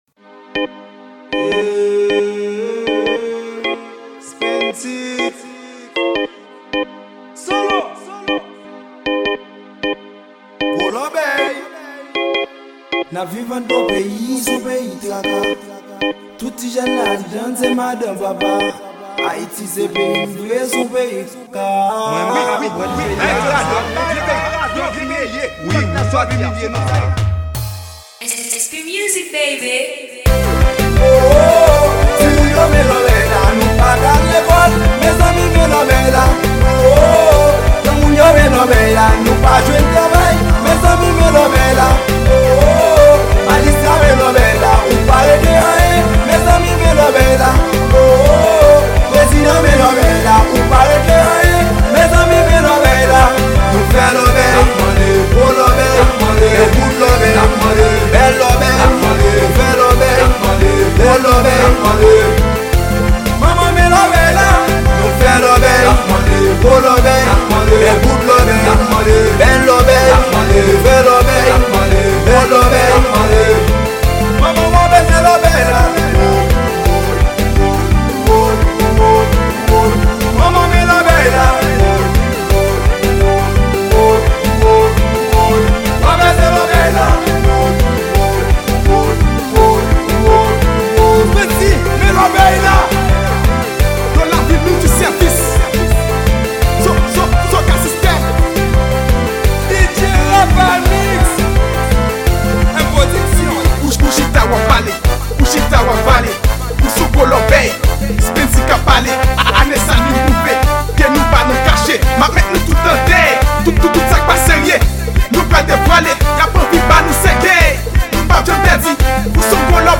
Genre : KANAVAL